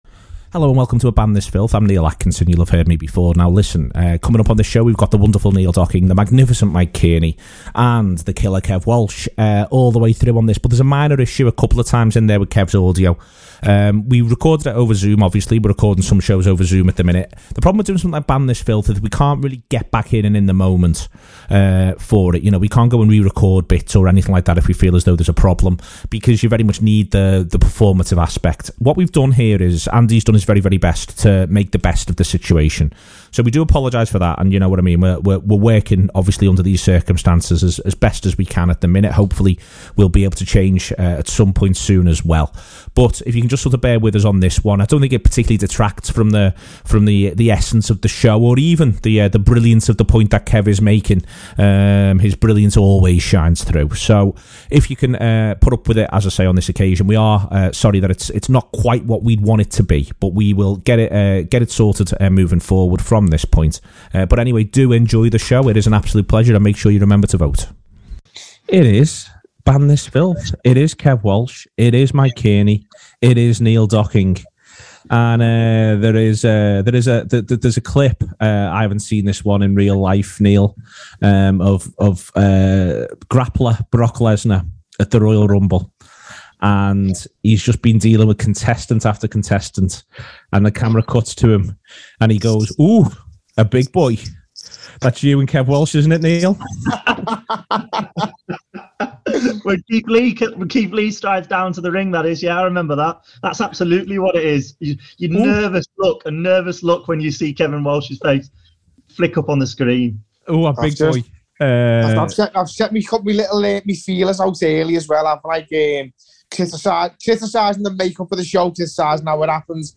Below is a clip from the show – subscribe for more on Liverpool number sevens…